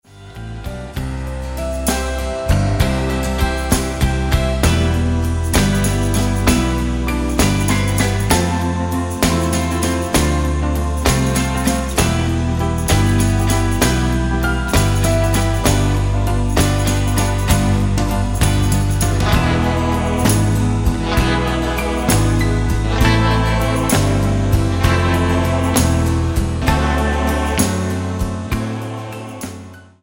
Tonart:C mit Chor
Die besten Playbacks Instrumentals und Karaoke Versionen .